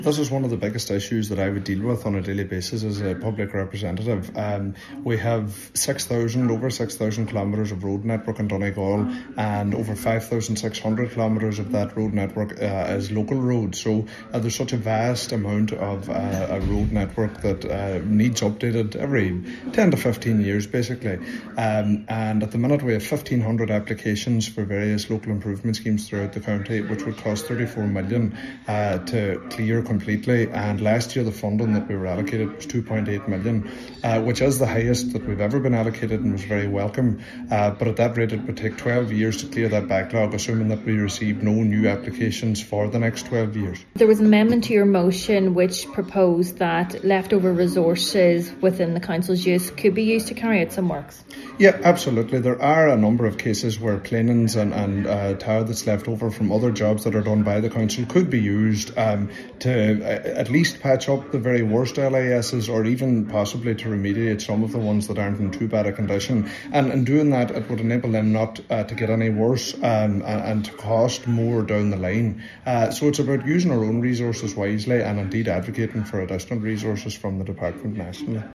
Councillor Bradley says the €2.8 million last allocated to the county falls well short of what is needed: